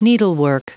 Prononciation du mot needlework en anglais (fichier audio)
Prononciation du mot : needlework
needlework.wav